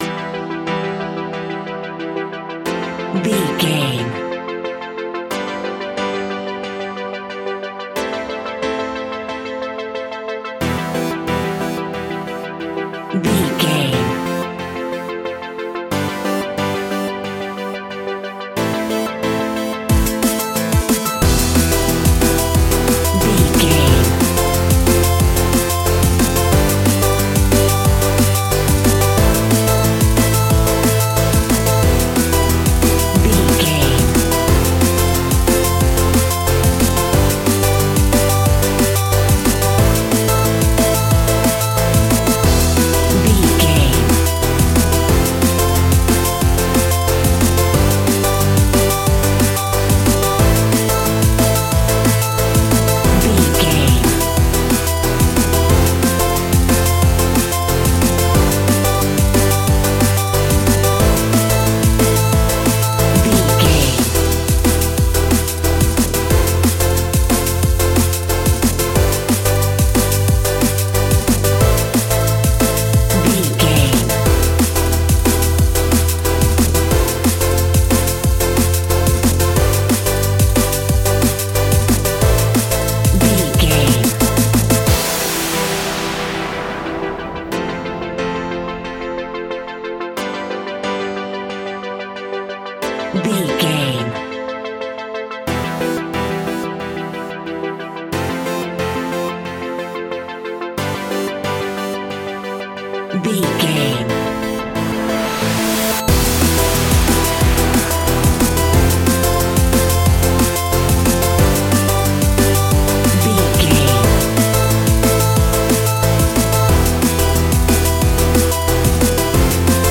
Ionian/Major
Fast
groovy
uplifting
futuristic
driving
energetic
repetitive
drum machine
synthesiser
electronic
sub bass
synth leads
synth bass